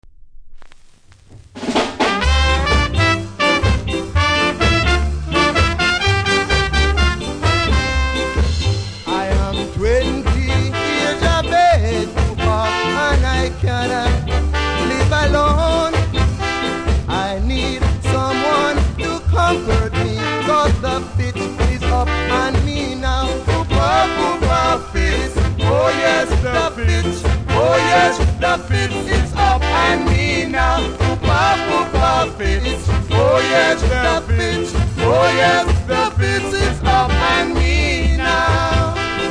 両面多少ノイズありますがキズは少なめです。